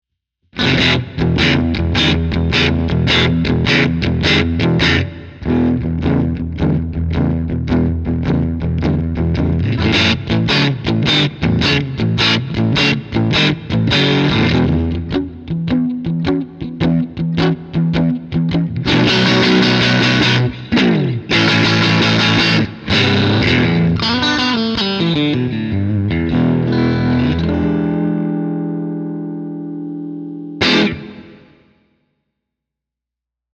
All audio clips are recorded with a Marshall JCM900 head, set on an extremely clean tone. The cabinet used is a 2×12 openback with Celestion Creamback 75 speakers.
Clean sound, no effects added
Guitar: Fender
Mode: Super
Gain: 9/10